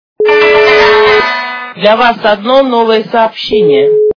» Звуки » звуки для СМС » Китайское СМС - Для Вас одно новое сообщение
При прослушивании Китайское СМС - Для Вас одно новое сообщение качество понижено и присутствуют гудки.